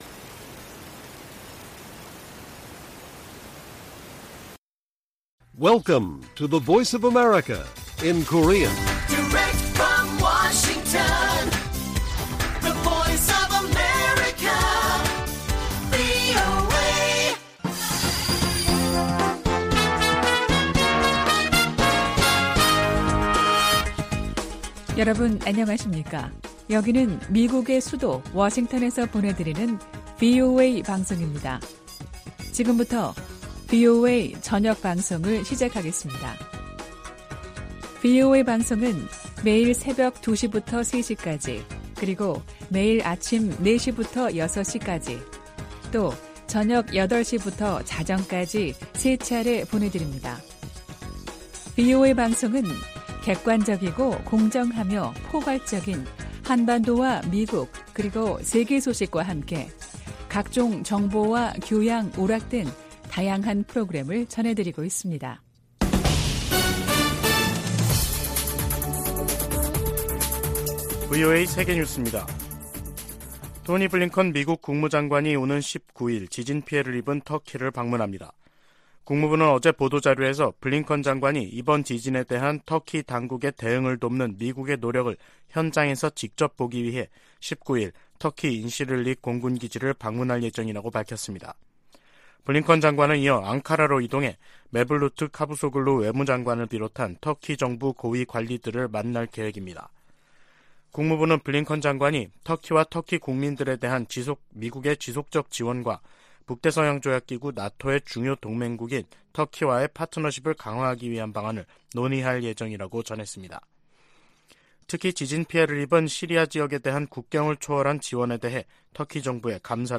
VOA 한국어 간판 뉴스 프로그램 '뉴스 투데이', 2023년 2월 16일 1부 방송입니다. 한국 정부의 국방백서가 6년만에 북한 정권과 군을 다시 적으로 명시했습니다. 미 국무부의 웬디 셔먼 부장관이 한국, 일본과의 외교차관 회담에서 북한의 도발적인 행동을 규탄하고 북한의 외교 복귀를 촉구했습니다. 미 하원에서 다시 재미 이산가족 상봉 결의안이 초당적으로 발의됐습니다.